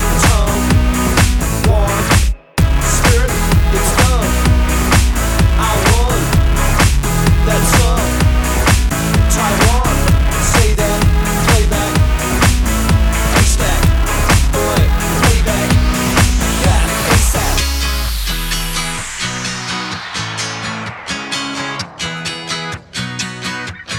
Duet Version R'n'B / Hip Hop 4:07 Buy £1.50